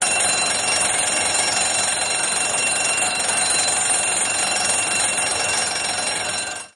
Alarms, Medium Pitched Burglar Alarm.